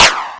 Index of /90_sSampleCDs/Club_Techno/Percussion/Clap
Clap_09.wav